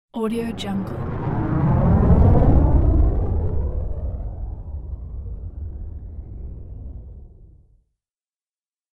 دانلود افکت صوتی عبور ستاره دنباله دار با سرعت زیاد 2